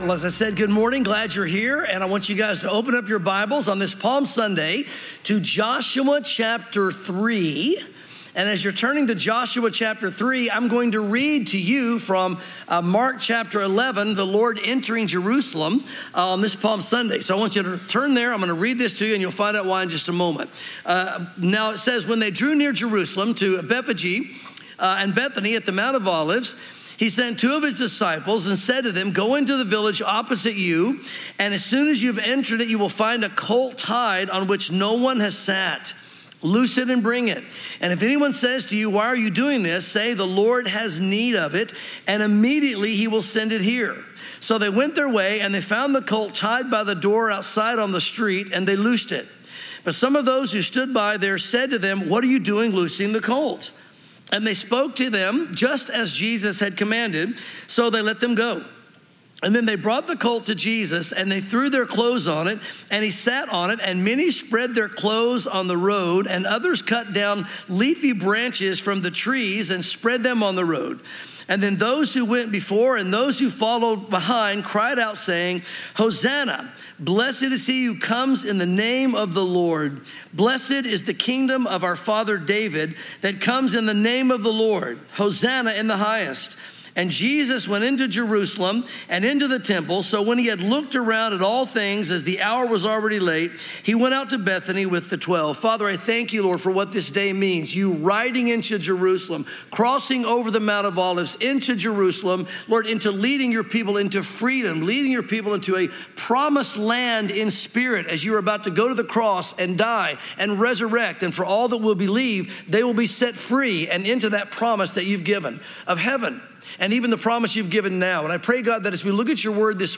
Palm Sunday